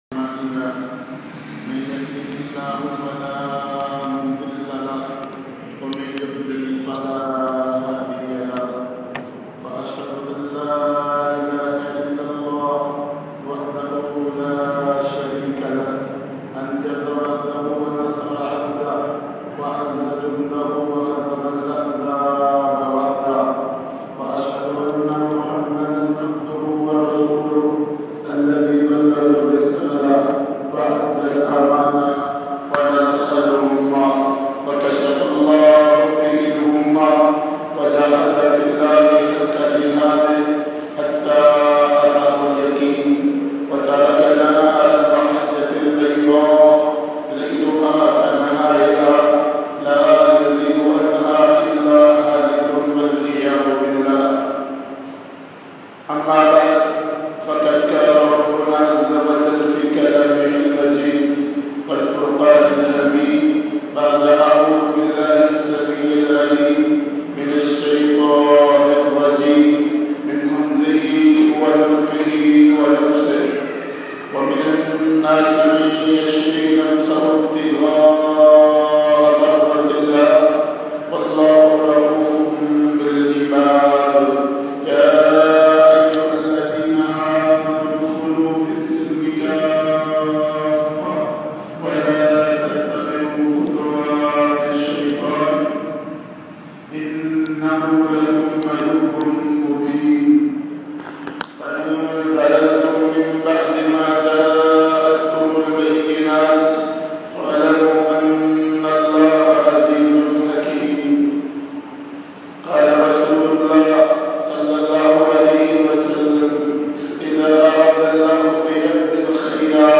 ISLAM NIZAM DEEN AUR REHMAT ELAHI bayan mp3